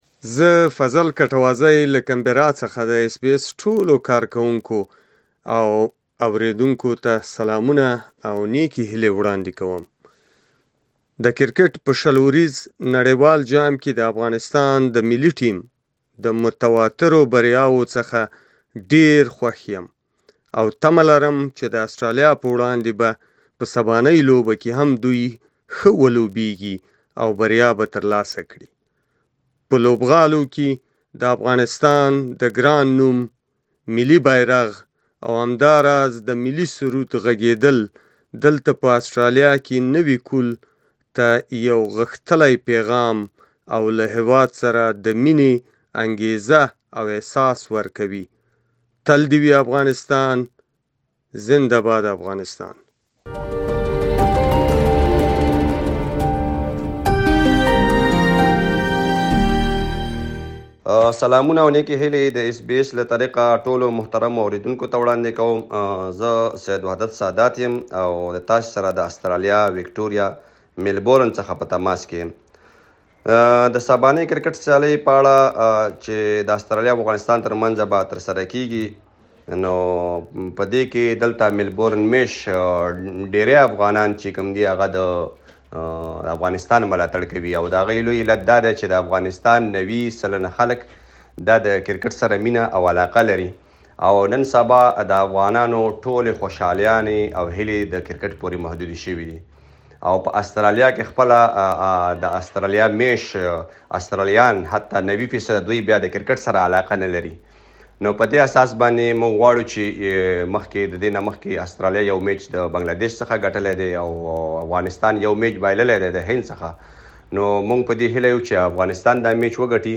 د روان کال په لومړیو کې آسټرالیا په افغانستان کې د ښځو او نجونو د وضعیت له امله افغان لوبډلې سره د شل اوریزو لوبو سیریز وځنډاوه. مګر دواړو لوبډلې سبا په نړیوال جام کې مخامخ کېږي. په آسټرالیا کې یو شمېر مېشتو افغانانو له اس بي اس پښتو سره د دغې لوبې په اړه خپل نظرونه شریک کړي دي.